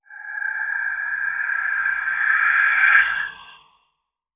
Efecto de terror: aparición fantasmal
fantasmal
terror
Sonidos: Especiales
Sonidos: Fx web